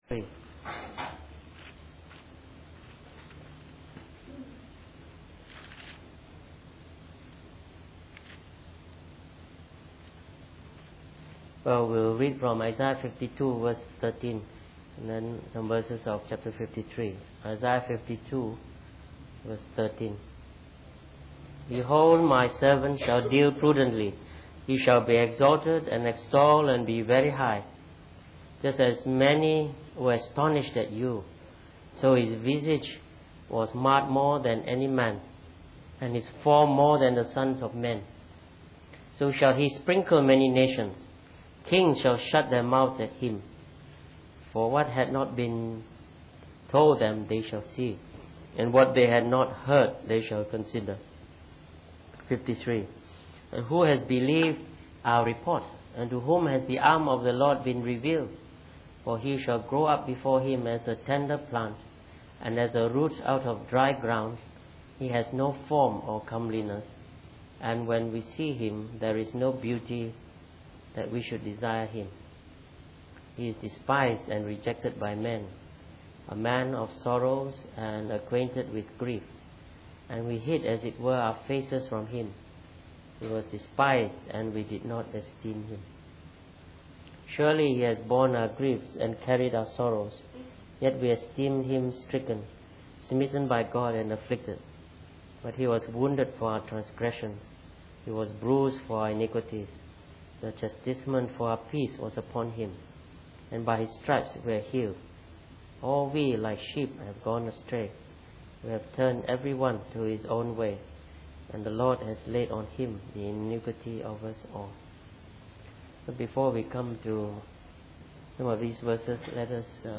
Preached on the 4th of December 2011.